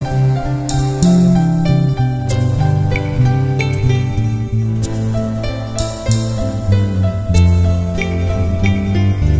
Músico, Arreglista, Guitarrista y Compositor